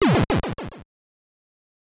Super C SFX (13).wav